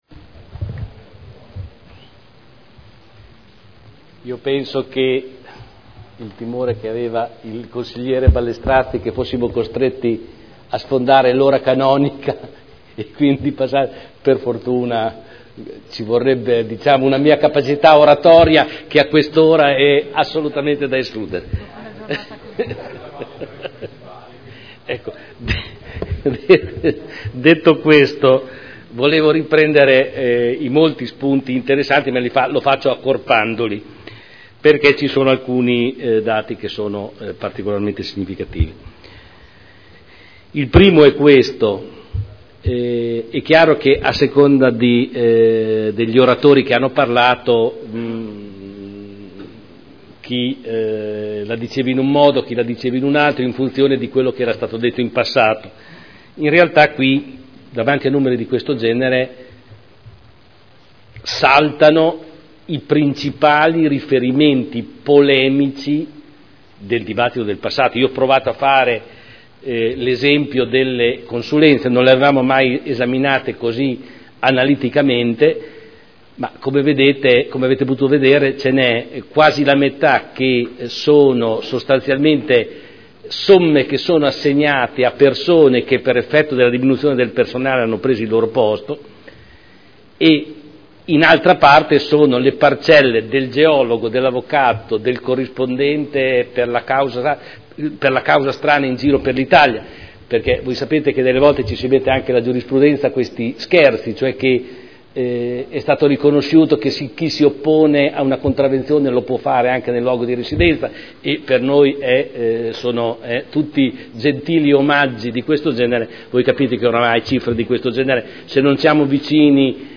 Seduta del 26 settembre 2011 Ricadute della manovra del Governo sul Bilancio del Comune di Modena – Conclusioni